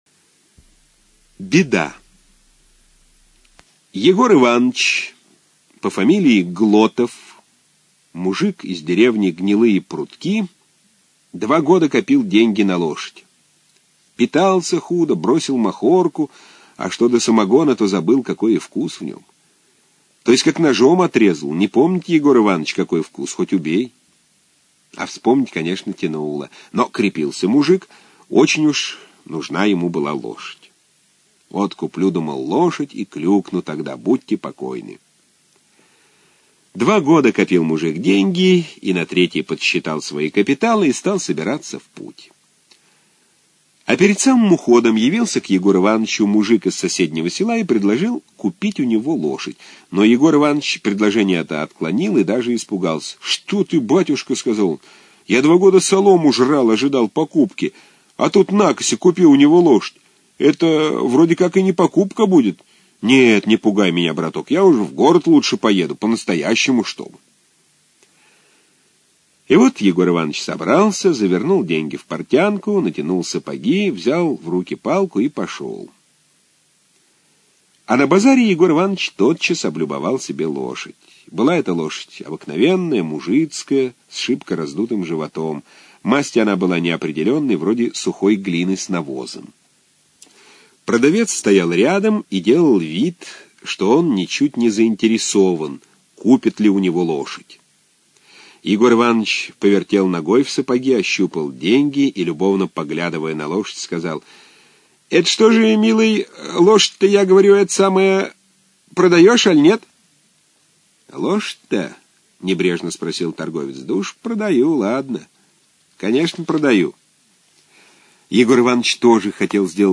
Аудиорассказ «Беда»